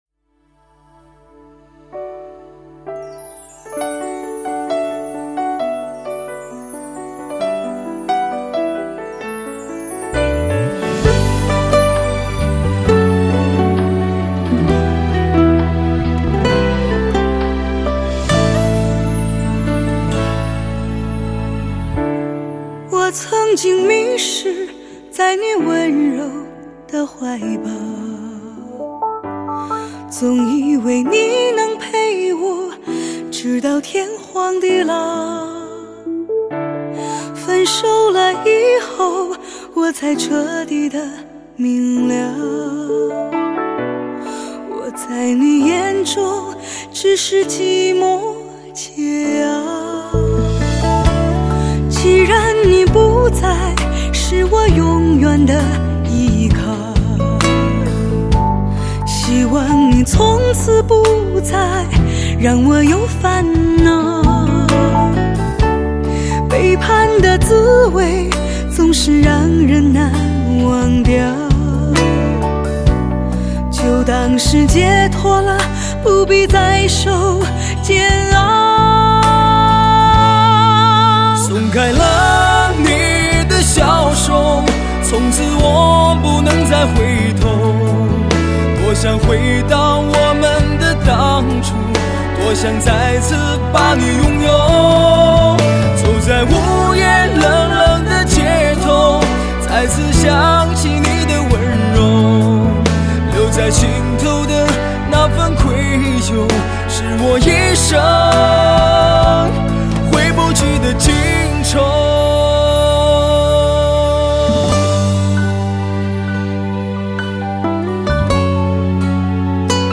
经典情歌，百听不厌，熟悉的旋律，给你最深处的感动。